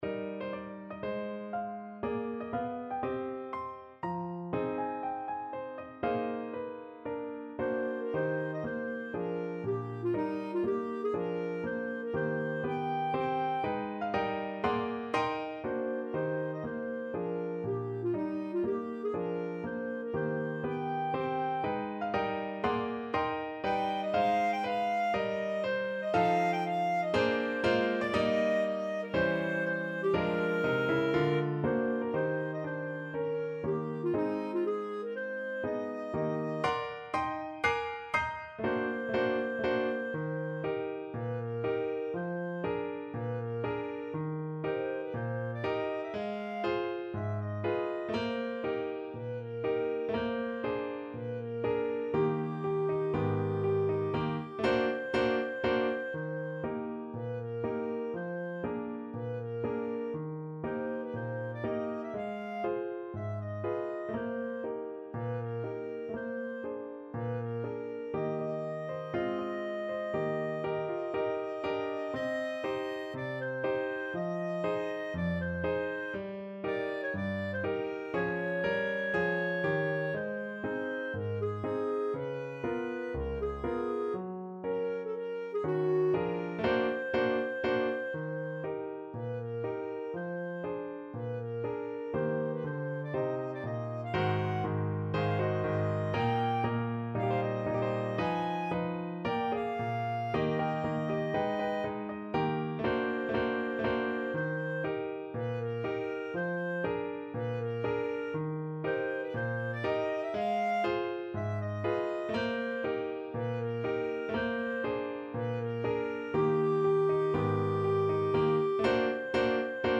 ~ = 120 Moderato
4/4 (View more 4/4 Music)